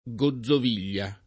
gozzov&l’l’a] s. f. — per la -z- sorda la maggioranza dei dizionari e la più accreditata etimologia gozzo (come fondam. unico o almeno come incrocio); per la sonora l’altra possibile derivaz. da un lat. mediev. gaudibilia — ugualm. rappresentate le due pronunzie in Tosc., senza chiare divis. all’interno, e a Roma